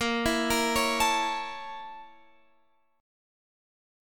A#mM7b5 chord